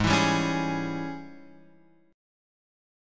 G#M7sus4#5 chord {4 7 5 6 5 x} chord